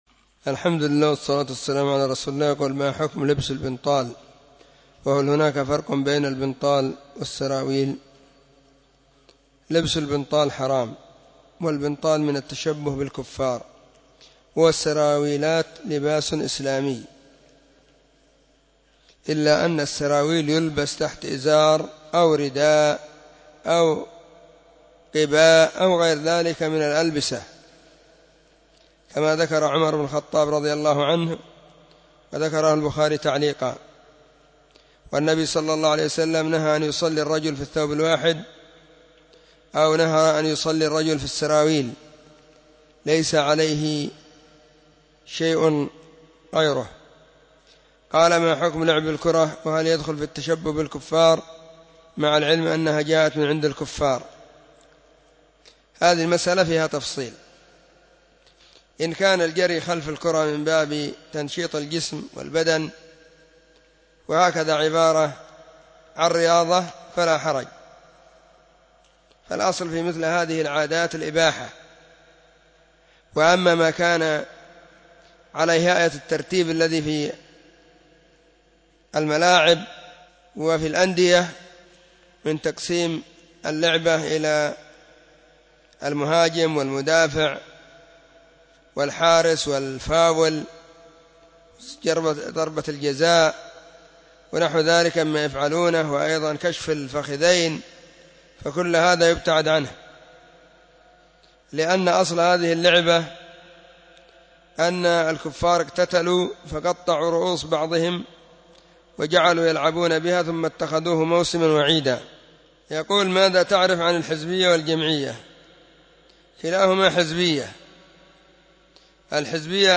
فتاوى الإثنين 29 /محرم/ 1443 هجرية. ⭕ أسئلة ⭕ -6